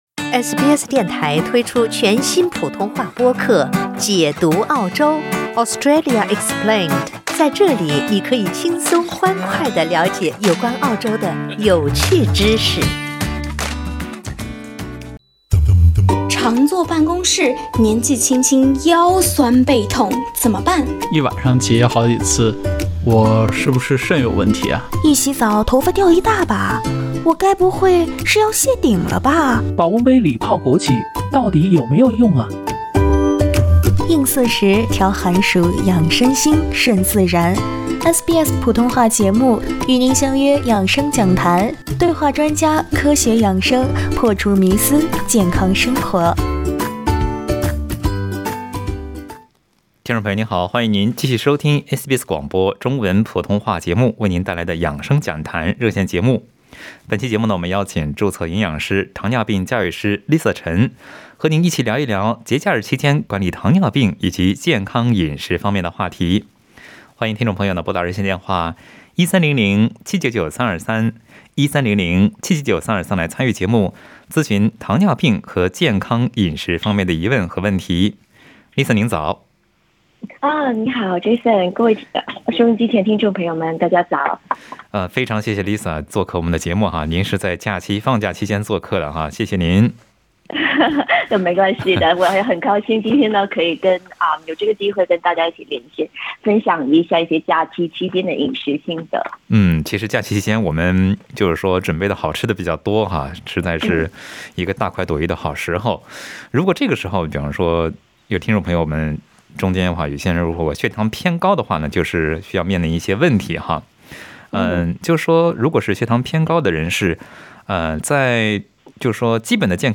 在节目中，听友们咨询了主食、食用油、蛋白质选择等问题